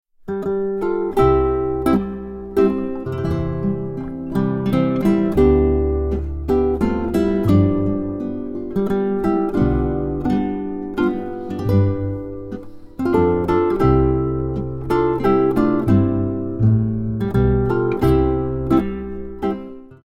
Adventmusik